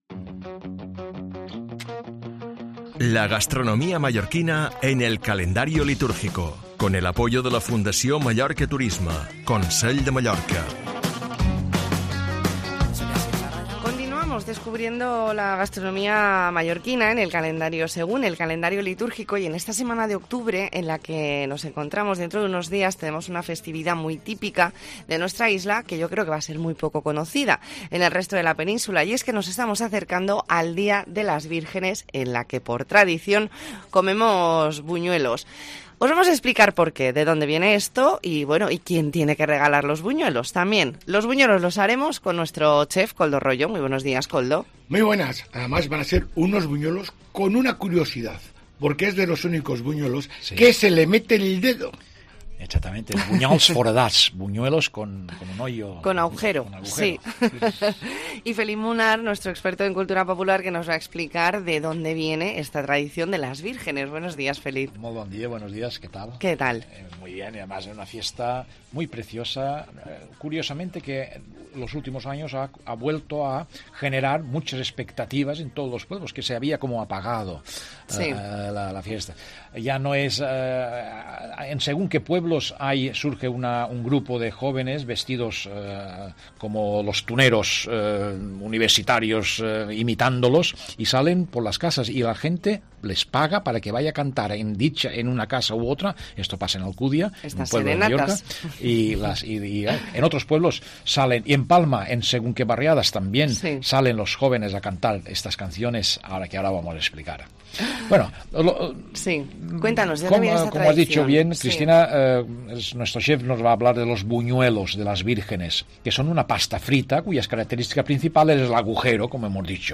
Espacio de historia, tradición y gastronomía con el chef
el experto en cultura popular